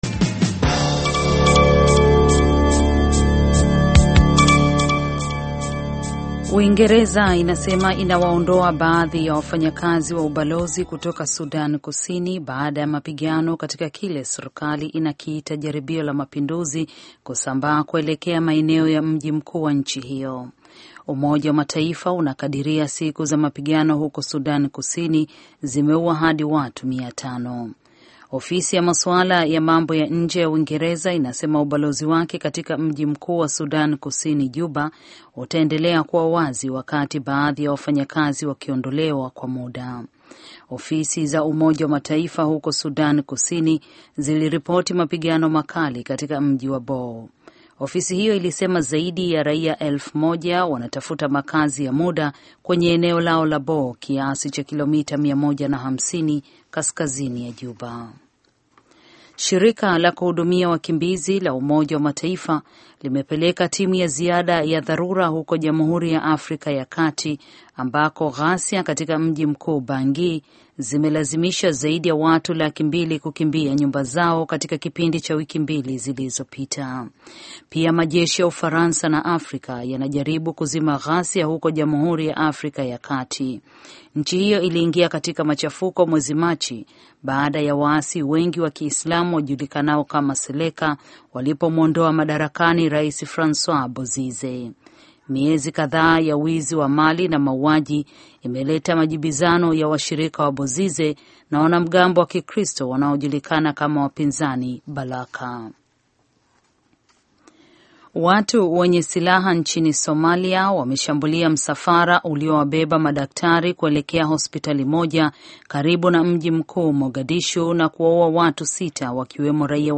Taarifa ya Habari VOA Swahili - 5:11